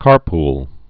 (kärpl)